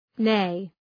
Προφορά
{neı}